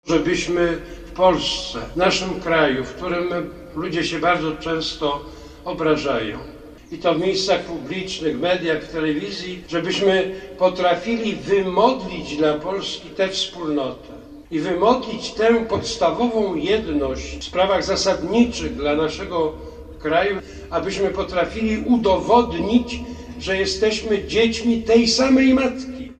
W kościele św. Anny na Krakowskim Przedmieściu studentów i uczniów pożegnał kardynał Kazimierz Nycz.
Metropolita warszawski w homilii nawiązał do motta tegorocznej pielgrzymki: „Synu, oto Matka Twoja”. Duchowny prosił, aby jedna z intencji, w której będą się modlili pielgrzymi, dotyczyła życia społecznego.